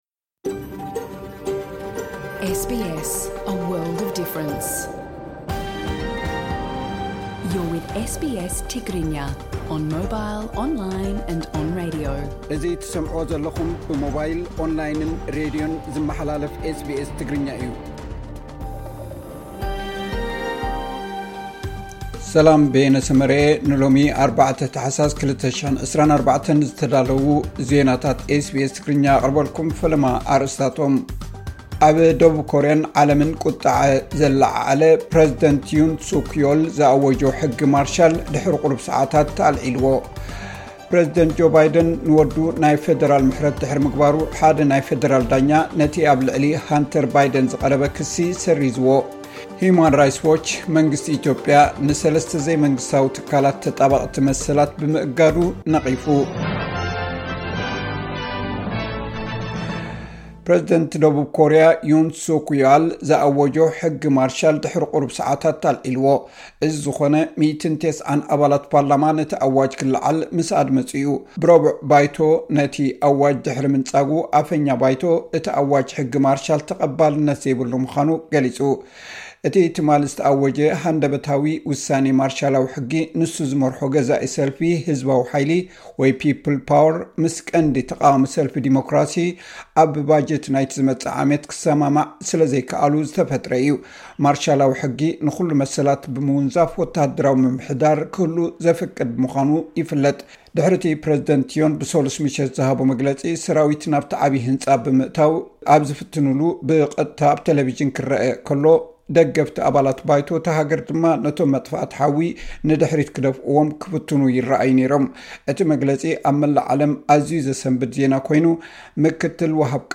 መራሒ ደቡብ ኮርያ ናብ ኣምባገንነት፧ ዕለታዊ ዜናታት ኤስ ቢ ኤስ ትግርኛ (05 ታሕሳስ 2024)